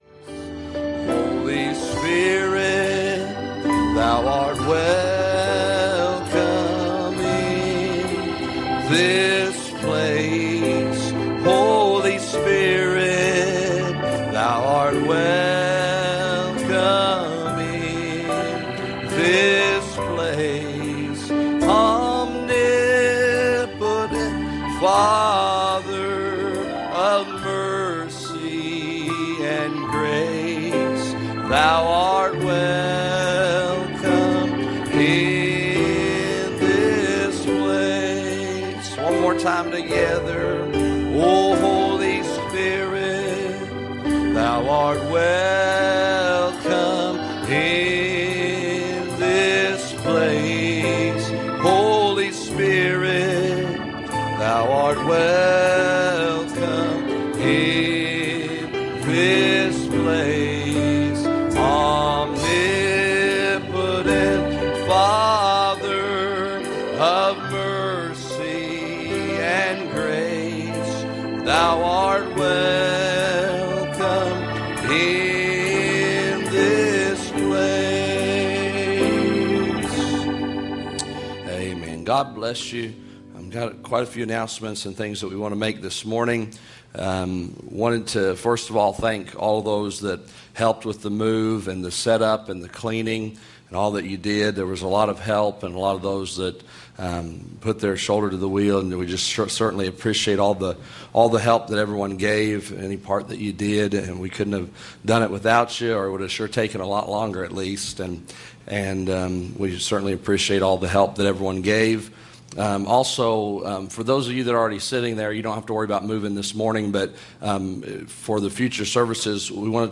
Series: Sunday Morning Services
Service Type: Sunday Morning